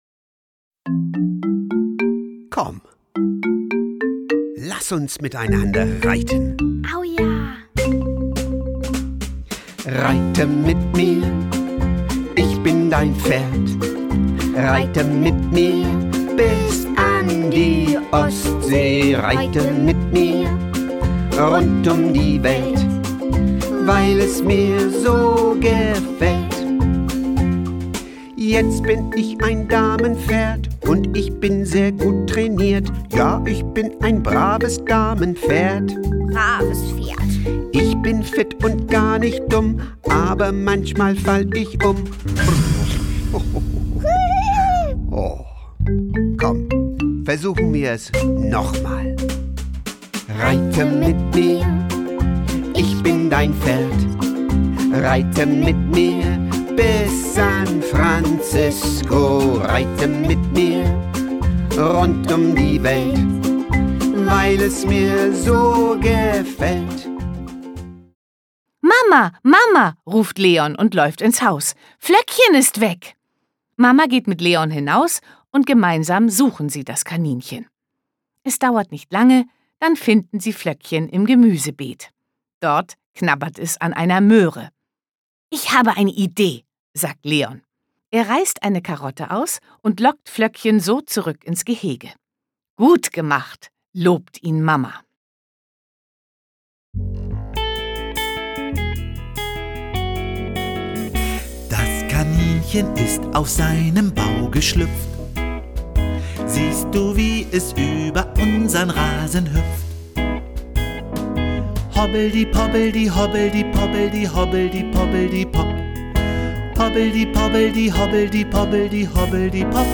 Schlagworte Bauer • Bauernhof • Ernte • Landwirtschaft • Minutengeschichten • mitraten • Nutztiere • Sachhörbuch • Sprachförderung • Tiere